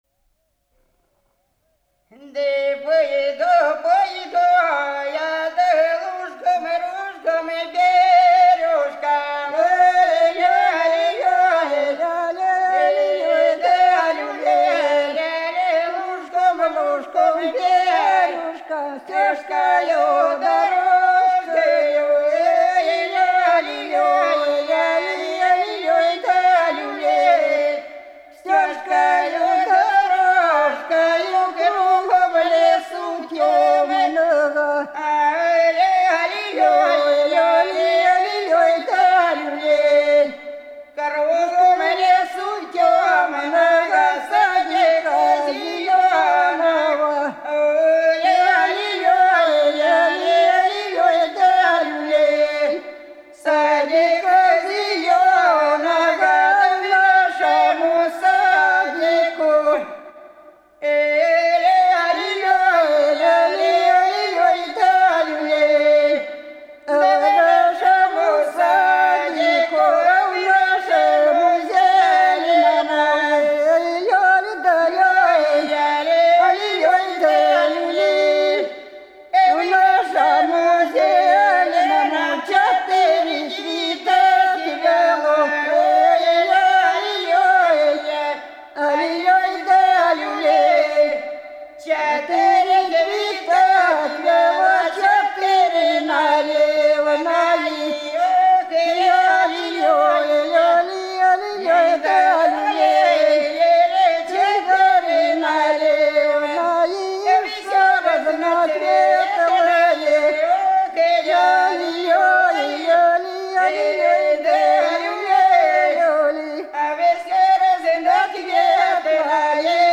Голоса уходящего века (Курское село Илёк) Да пойду, пойду я лужком-кружком-бережком (карагодная, в Петровский пост, с Красной Горки до осени в зимний мясоед, величают замужних)